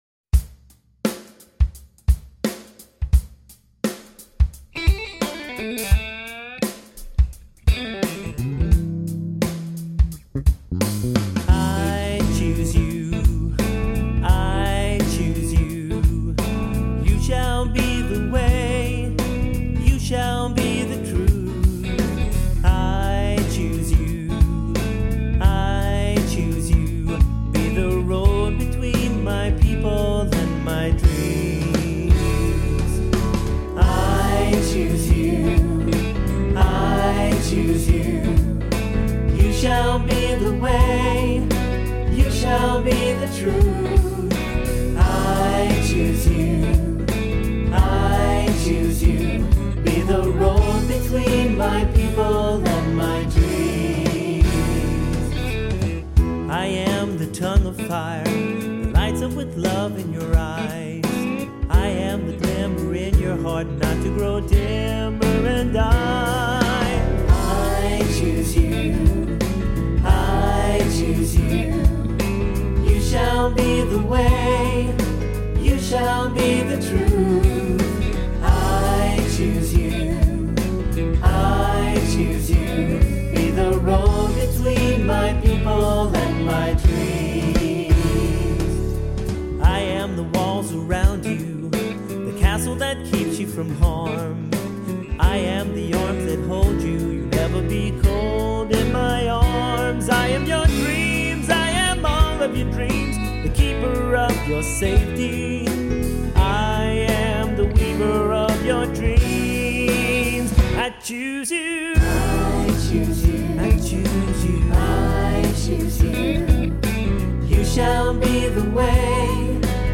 Voicing: 3-part Choir, cantor, assembly